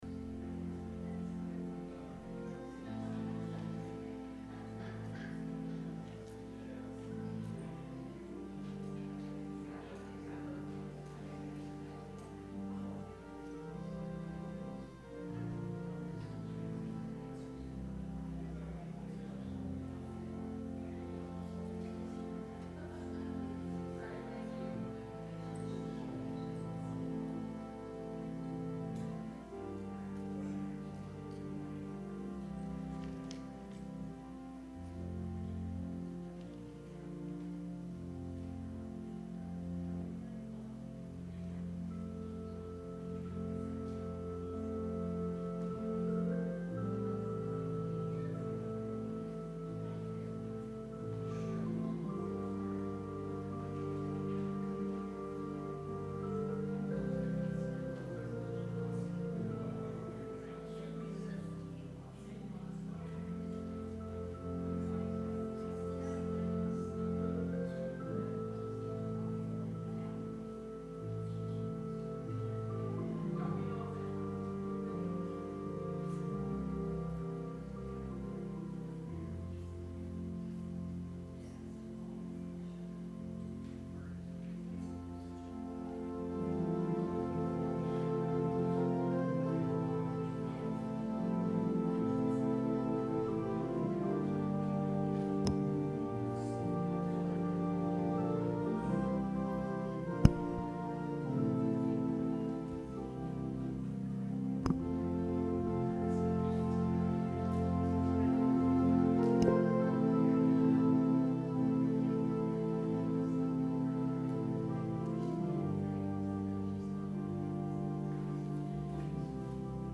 Sermon - June 9, 2019
advent-sermon-june-9-2019.mp3